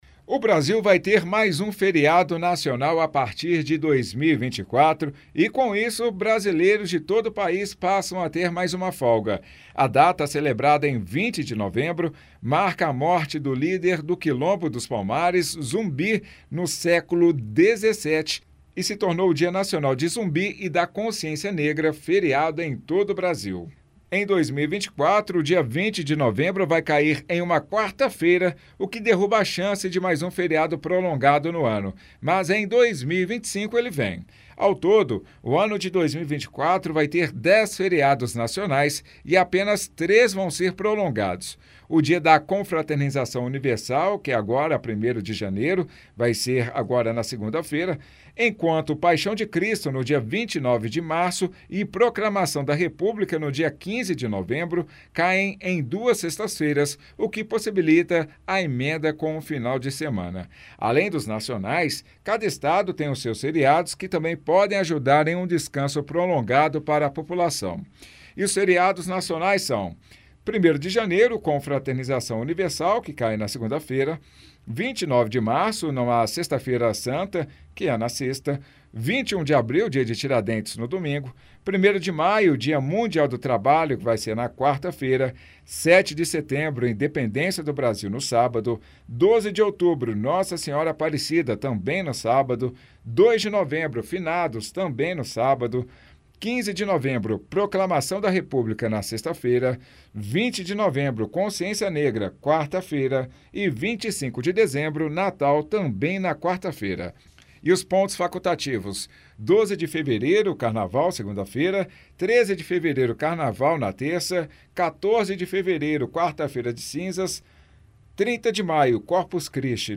Ícone do site Rádio FM Itatiaia